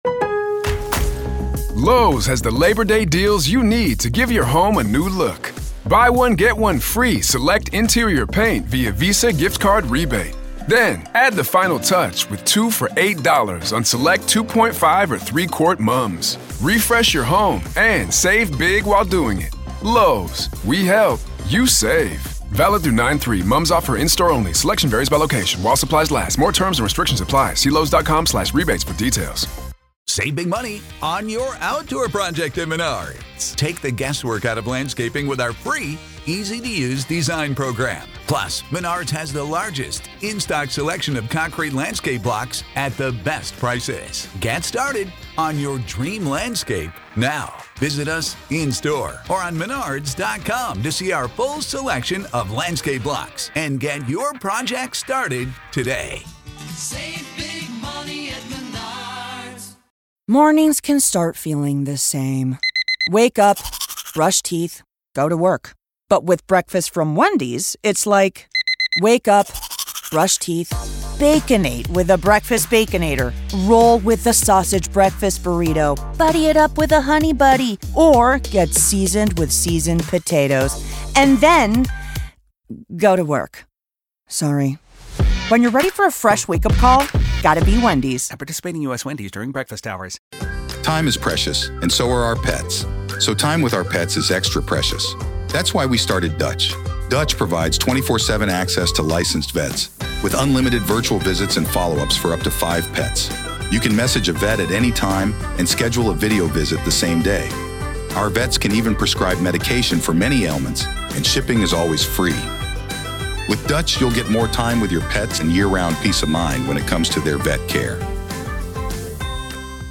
Full Interview: FBI Agent Breaks Down Bryan Kohberger’s Dark Psychology